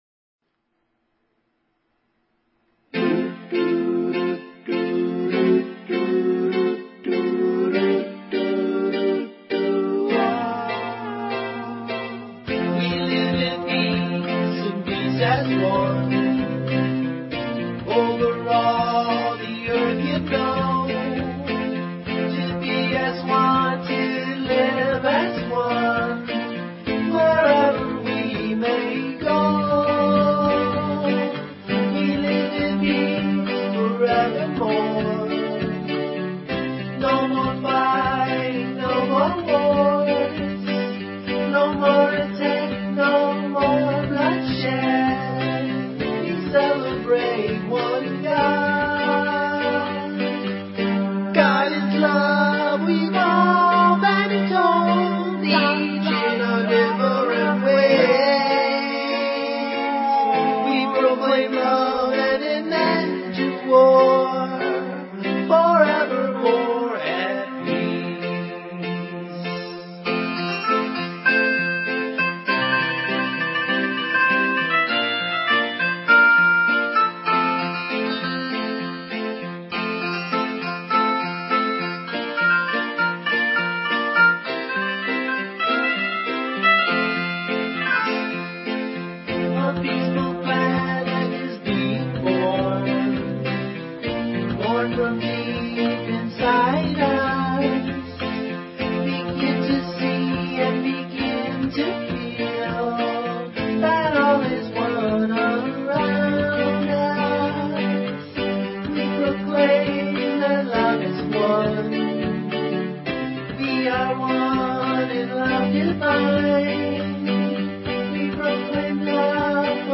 Talk Show Episode, Audio Podcast, Peaceful_Planet and Courtesy of BBS Radio on , show guests , about , categorized as